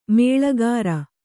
♪ mēḷagāra